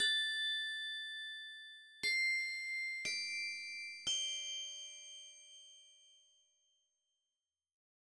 28 Bells PT4.wav